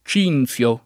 ©&nZLo] etn. stor. (del monte Cinto, Gr.); pl. m. -zi — anche cintio [©&ntLo]; pl. m. -ti (alla lat. -tii) — con C‑ maiusc. come epiteto di divinità greche (spec. Apollo e Artemide) — solo o quasi nella forma con -z‑ come pers.: Cinzio e (molto più com.) Cinzia — con -t- come cogn.: Cintia, -tio, Di CintioCinzio o Cintio come epiteto umanistico adottato (dapprima nella forma latina Cynthius [©&ntLuS]) dal letterato G. B. Giraldi (1504-73)